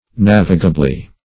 navigably - definition of navigably - synonyms, pronunciation, spelling from Free Dictionary
Nav"i*ga*bly, adv.
navigably.mp3